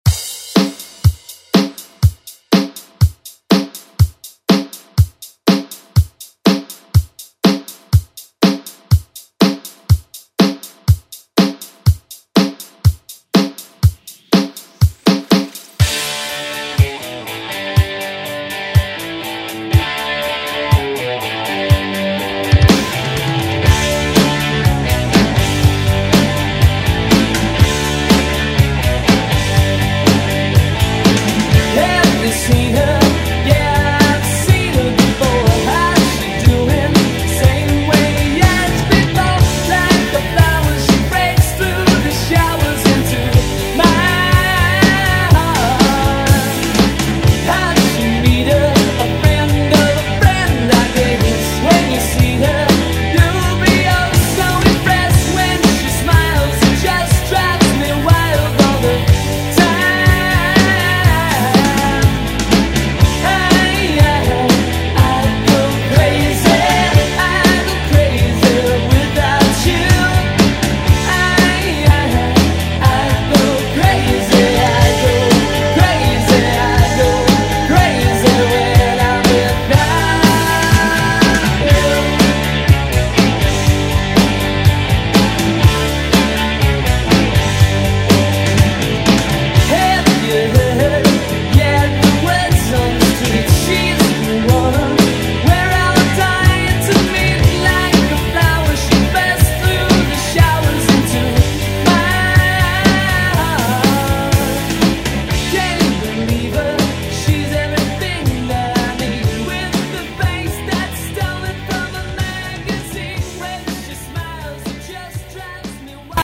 Electronic Pop Rock Music
108 bpm
Genres: 90's , RE-DRUM , ROCK Version: Clean BPM